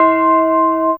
ChimesC2C4.wav